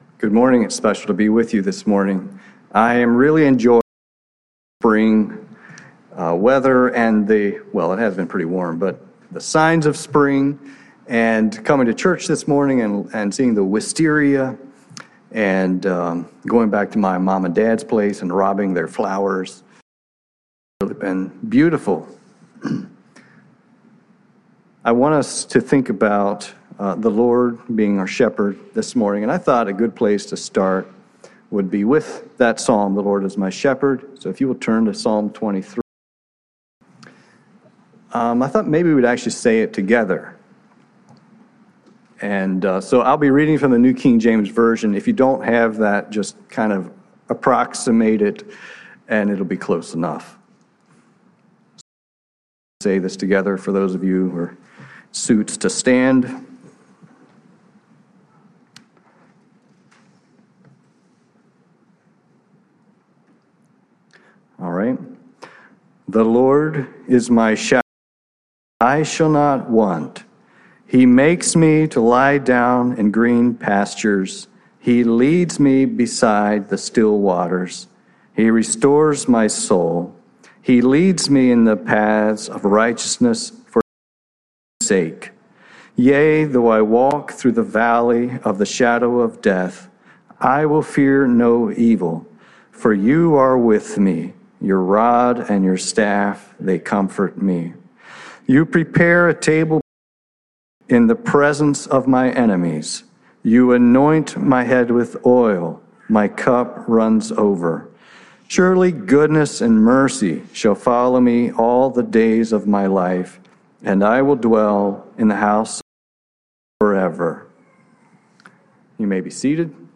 Bethel Mennonite Church - Gladys VA